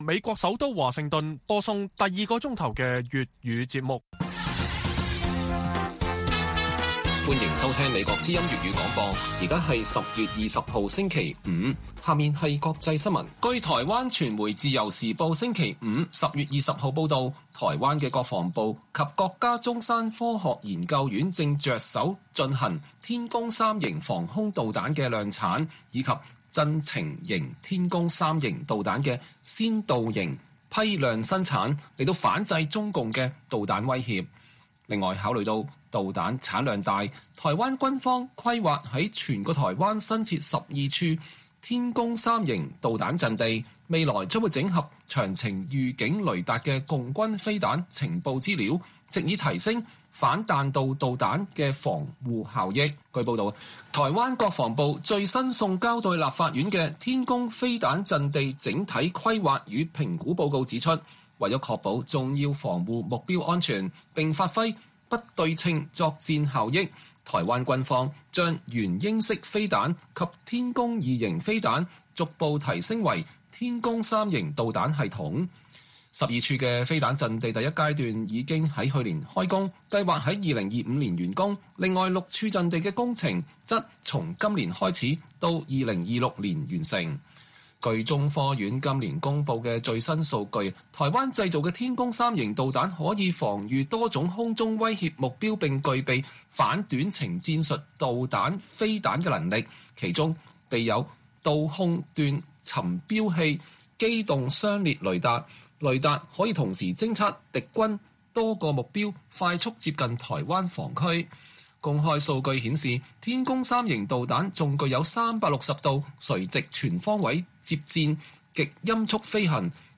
粵語新聞 晚上10-11點 : 美國高度關注台灣大選兩岸政策重中之重